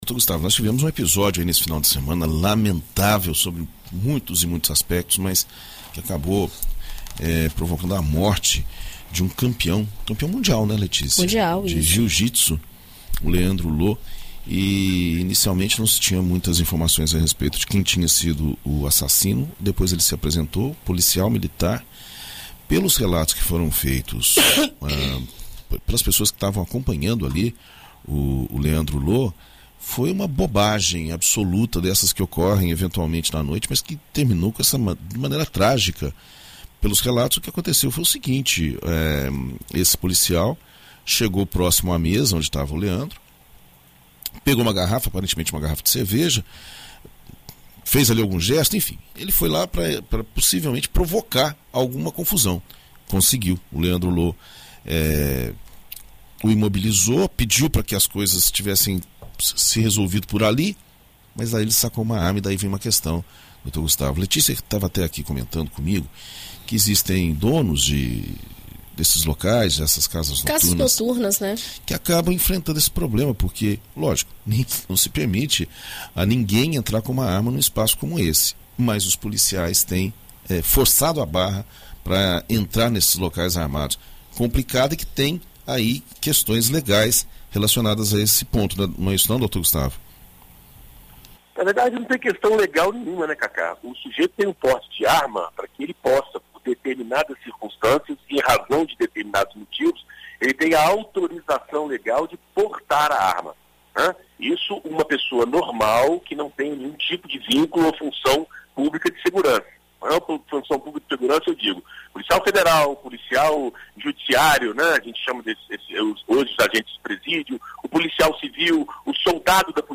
Na coluna Direito para Todos desta segunda-feira (08), na BandNews FM Espírito Santo,